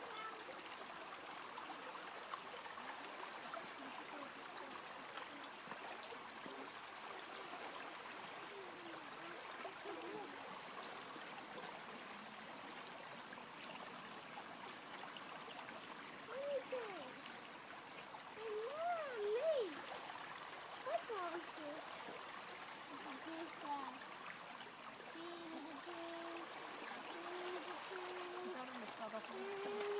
River sounds